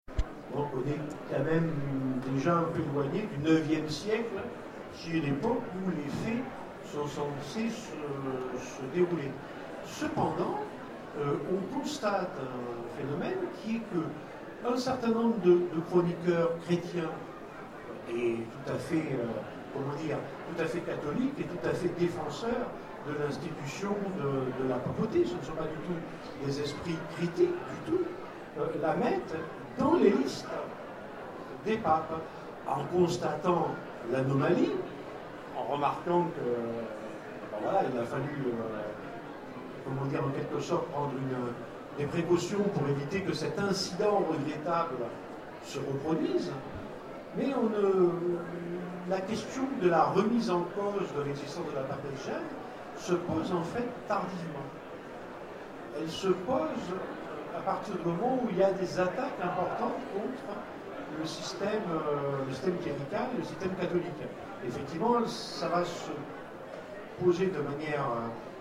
Zone Franche 2014 : Conférence La Papesse Jeanne, du mythe à la littérature populaire